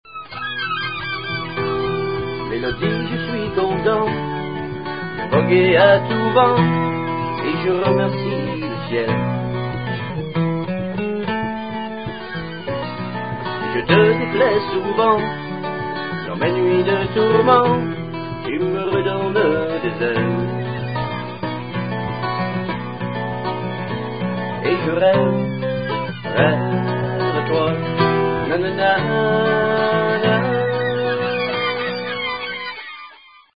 Sous l'archet, la corde du violon se fait parole de mouette.
cris de mouettes (au violon)